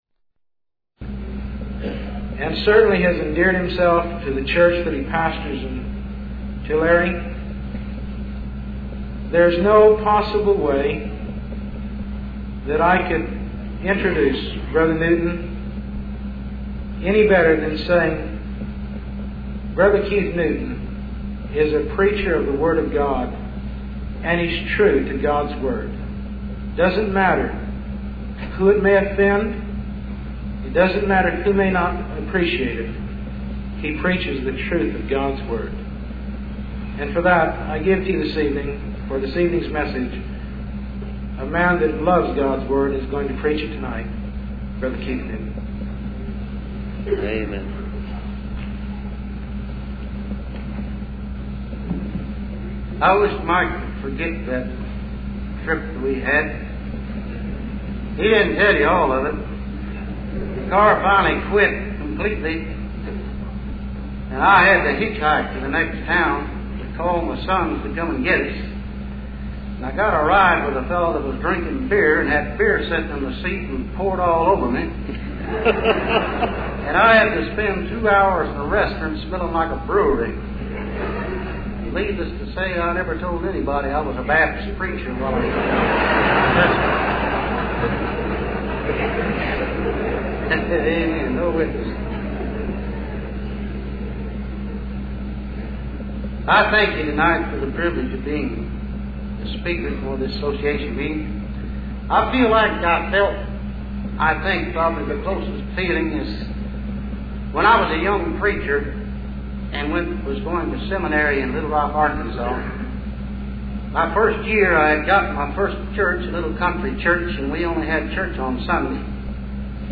Their was not a dry eye in the house and all went away revived by God's Word.